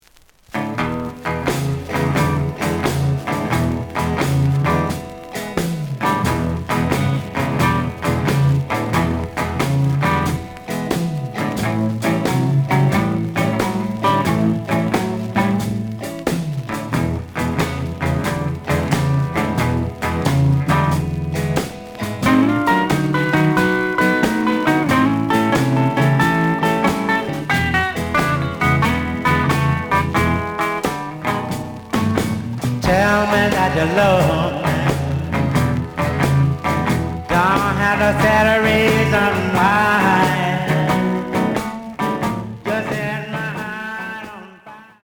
試聴は実際のレコードから録音しています。
The audio sample is recorded from the actual item.
●Genre: Blues